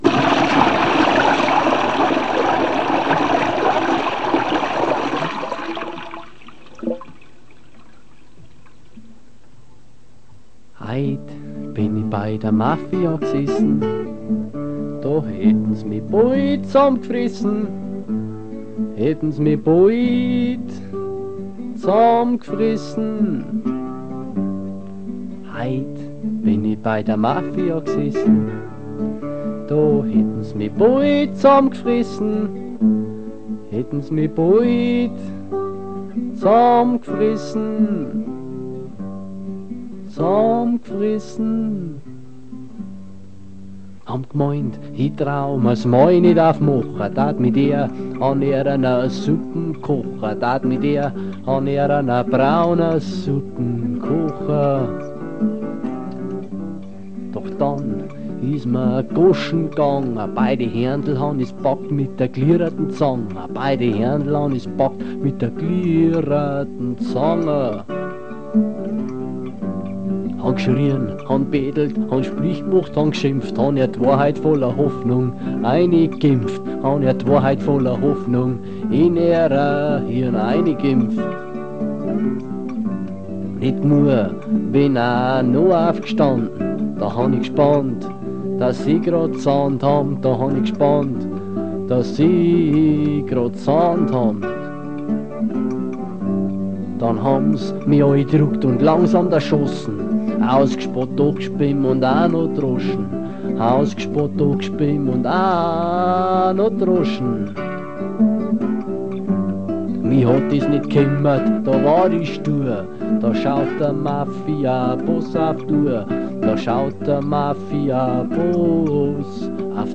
Vozwickte Mundartsongs